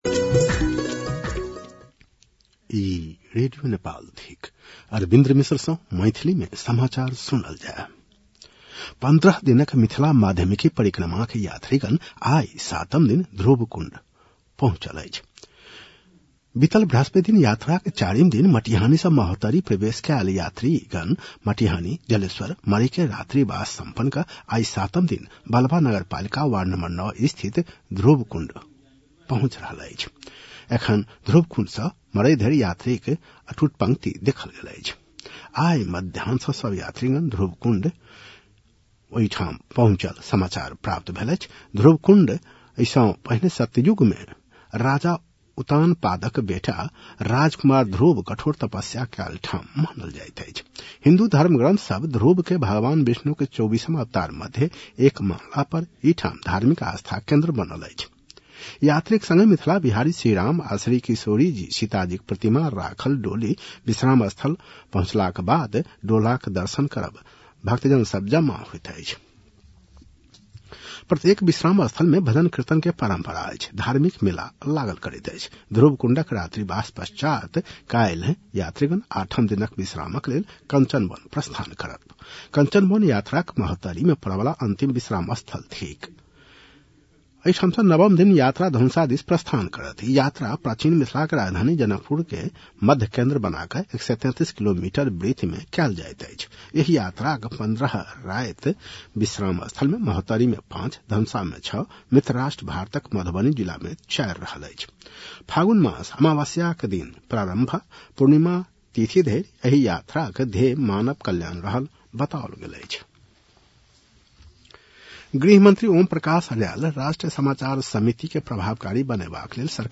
मैथिली भाषामा समाचार : १० फागुन , २०८२
Maithali-news-11-10.mp3